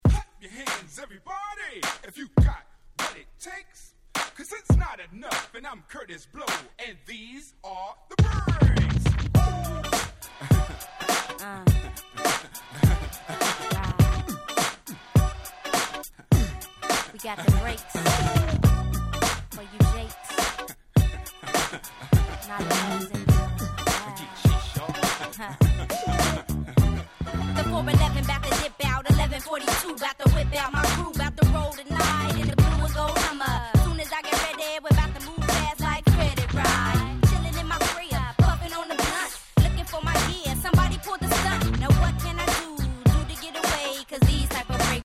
96' Smash Hit Hip Hop !!
決して悪くは無いし、寧ろキャッチーで受けの良い1曲ではありますが、いかんせん一発屋感が‥(笑)